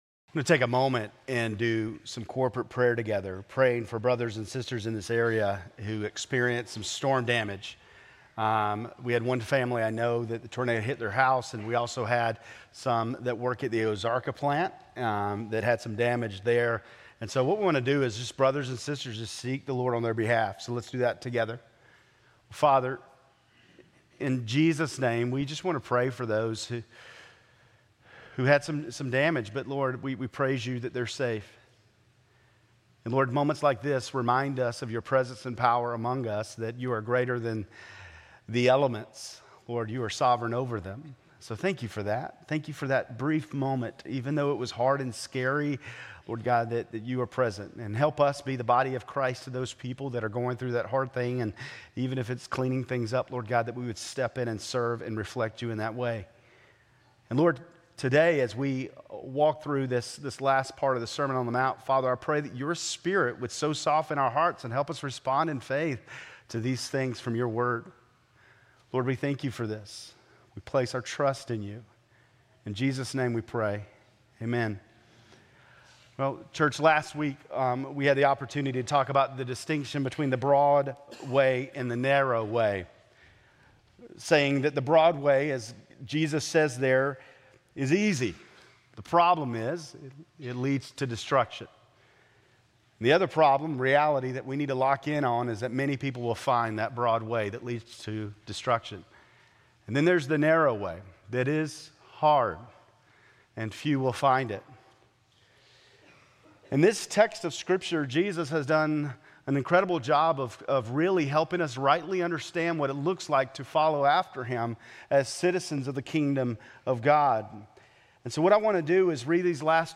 Grace Community Church Lindale Campus Sermons 4_6 Lindale Campus Apr 07 2025 | 00:28:21 1x 00:00 / 00:28:21 Subscribe Share RSS Feed Share Link Embed